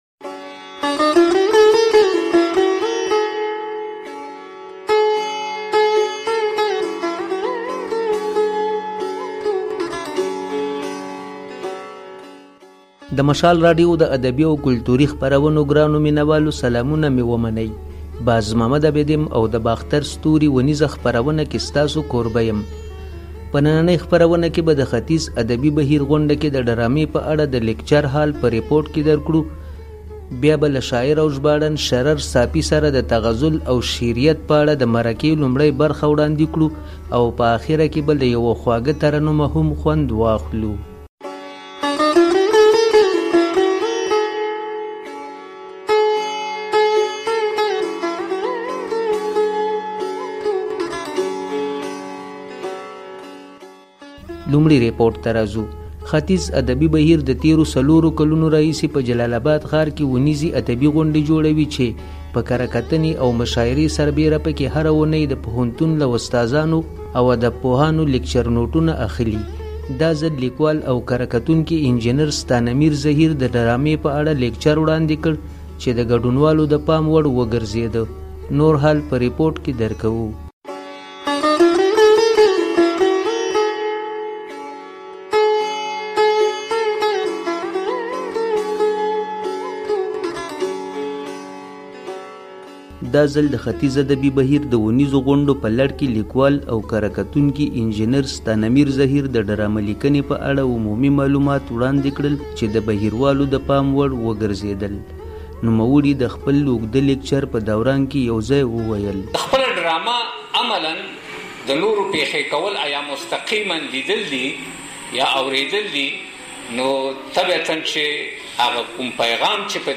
په يوه خواږه غږ ترنم ځای شوي دي.